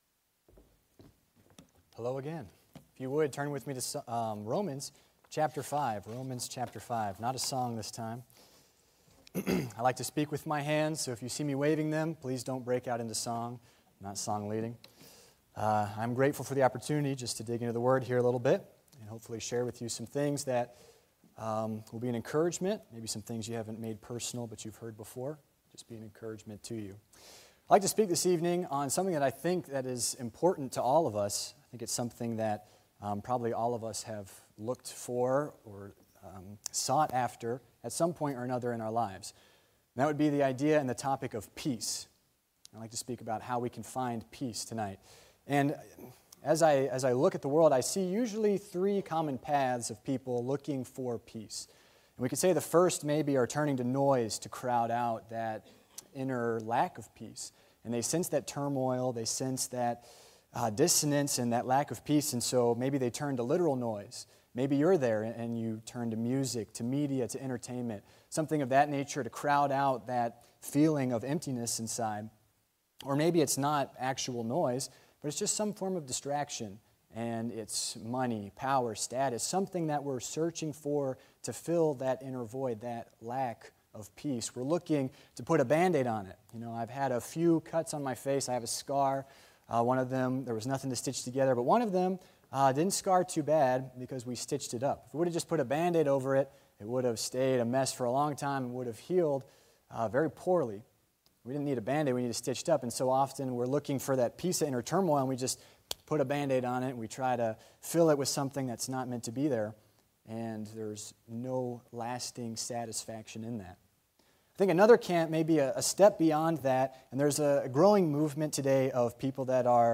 Passage: Romans 5:1 Service Type: Sunday PM « Is America A Christian Nation?